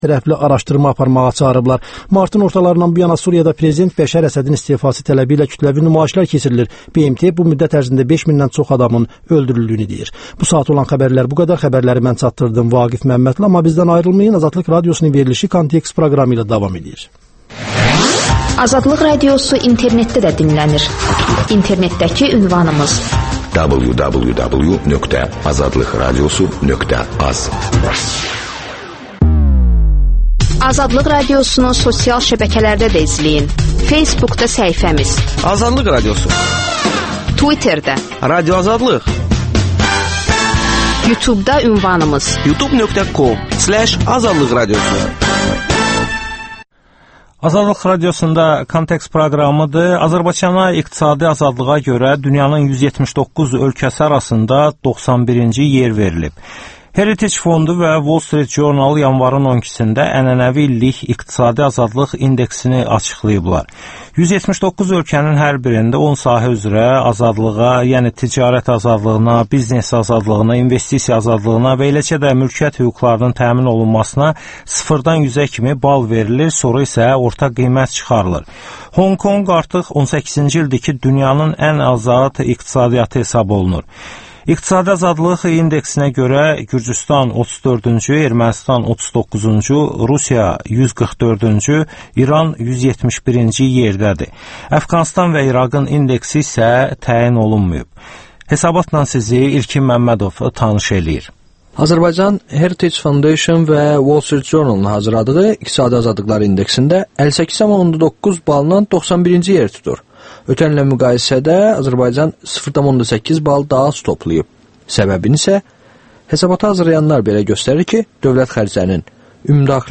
Bu və digər reportajlar, müsahibələr, hadisələrin müzakirəsi, təhlillər